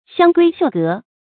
香閨繡閣 注音： ㄒㄧㄤ ㄍㄨㄟ ㄒㄧㄨˋ ㄍㄜˊ 讀音讀法： 意思解釋： 舊指女子的內室。